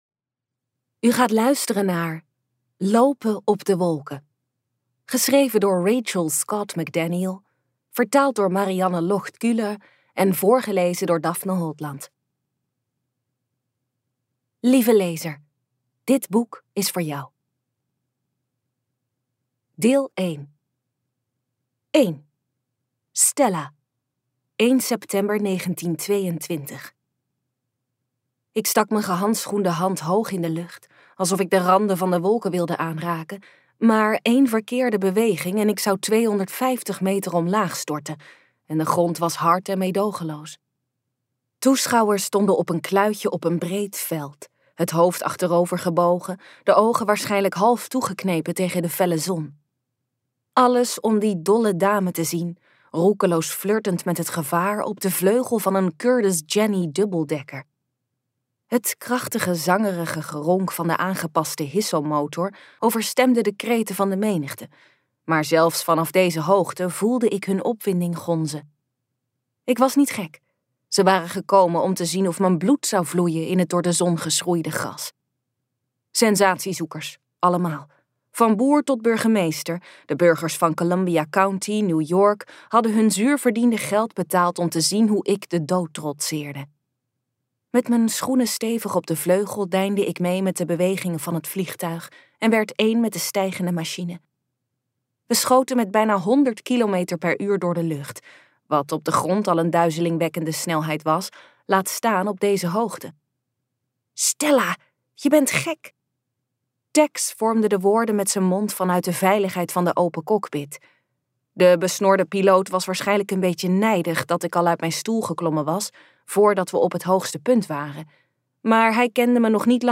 KokBoekencentrum | Lopen op de wolken luisterboek